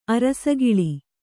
♪ arasagiḷi